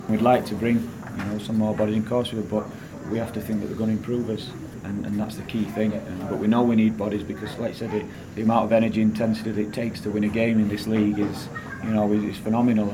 Reds gaffer was speaking after Saturday's Championship victory over Derby